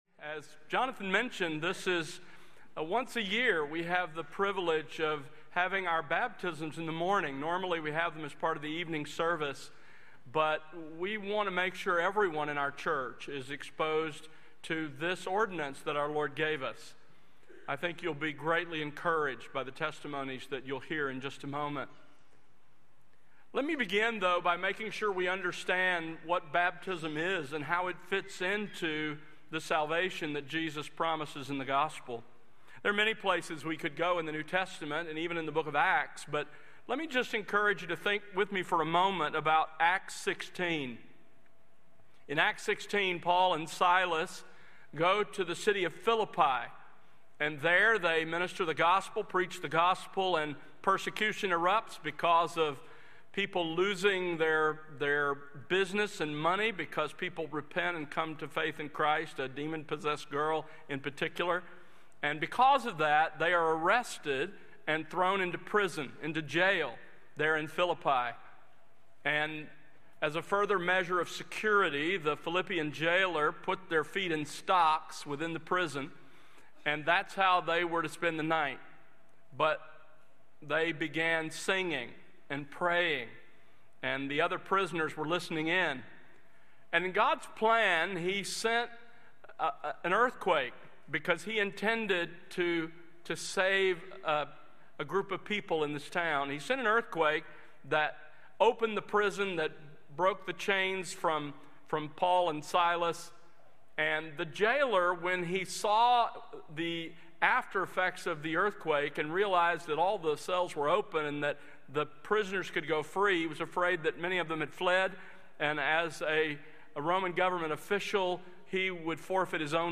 Morning Baptisms